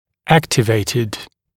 [‘æktɪveɪtɪd][‘эктивэйтид]активированный